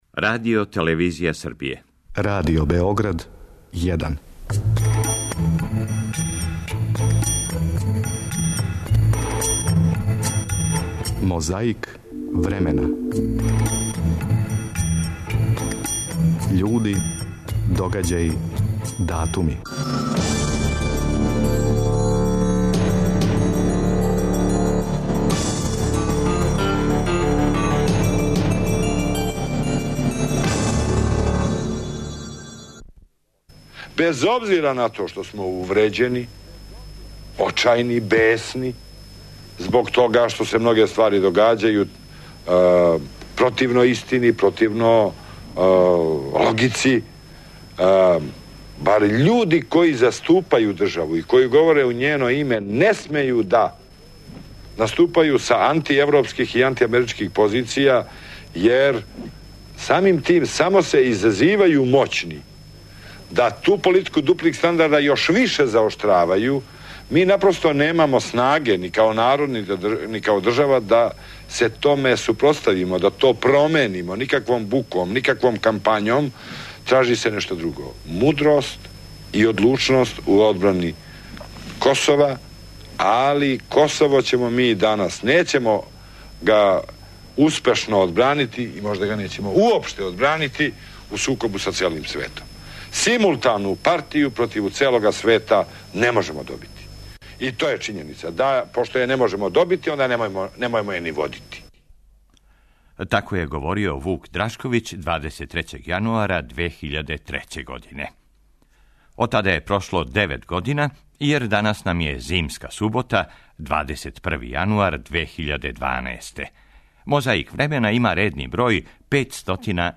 Гостовао је на БК телевизији 23. јануара 1999., а трака је ту да посведочи.
А за добро расположење ту је и један Титов говор. Овога пута са свечаног отварања Седмог конгреса НОЈ-а, 23. јануара 1963.